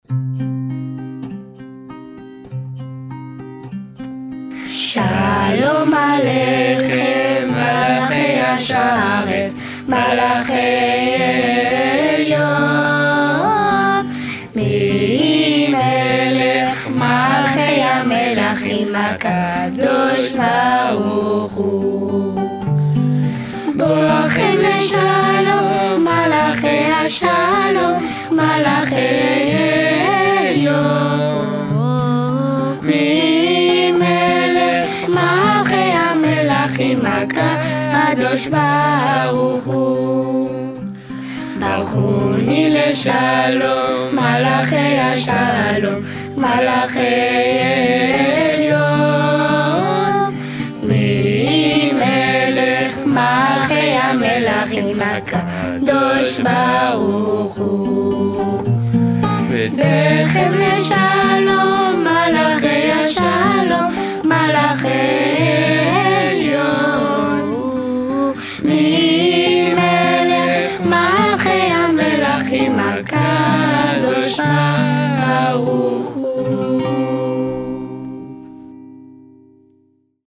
Chalom alékhem est un hymne que les Juifs ont coutume de chanter avant le Kiddouch du vendredi soir.
Audio Enfants: